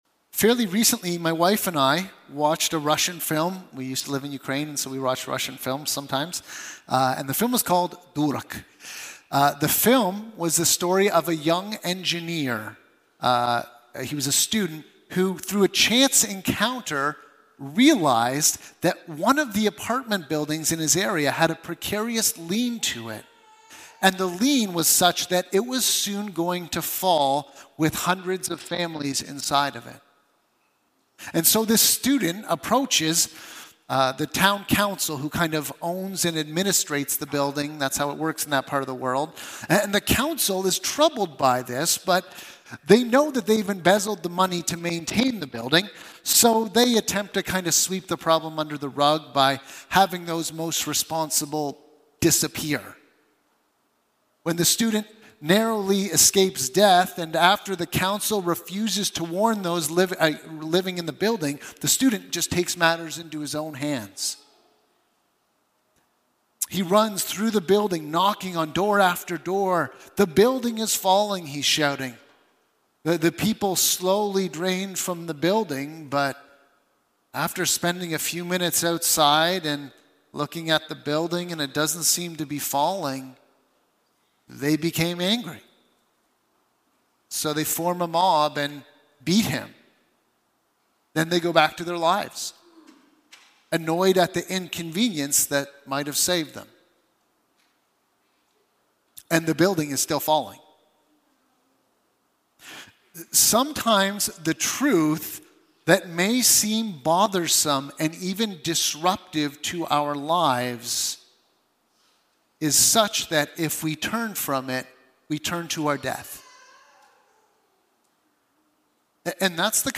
2025 Deuteronomy 2 BACK TO SERMON LIST Preacher